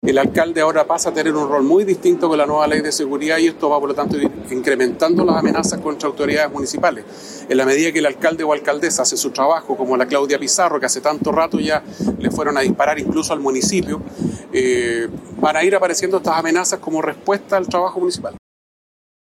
Las declaraciones se dieron en el contexto de un acuerdo de seguridad entre Santiago y Recoleta, instancia en la que las autoridades coincidieron en que el combate al delito requiere coordinación intercomunal, pero también mayores garantías de protección para quienes encabezan las acciones contra el crimen organizado en los territorios.